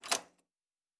Fantasy Interface Sounds
Locker 7.wav